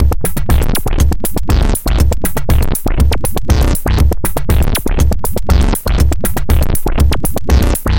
标签： 120 bpm Electronic Loops Groove Loops 1.35 MB wav Key : Unknown
声道立体声